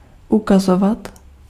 Ääntäminen
France: IPA: [mɔ̃ .tʁe]